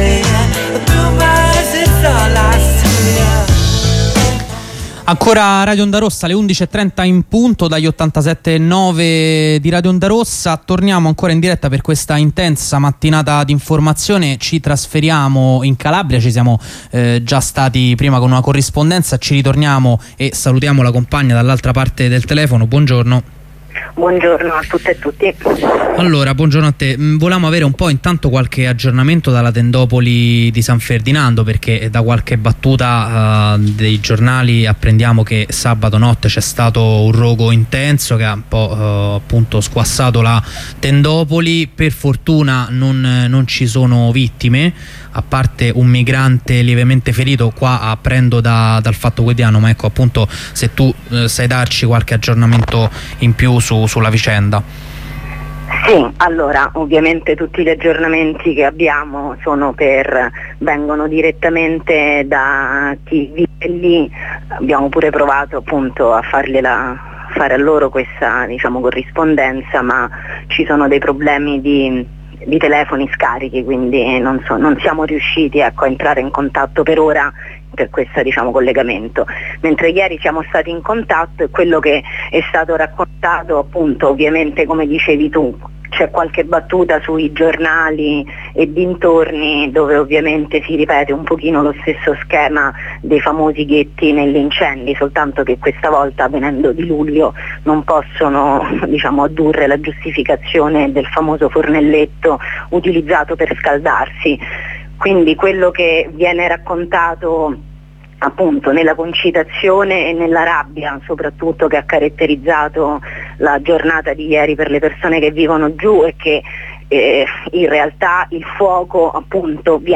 Ci aggiorna sulla situazione una compagna di Campagne in Lotta.